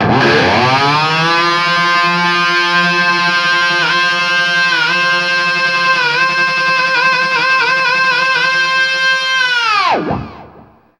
DIVEBOMB 4-L.wav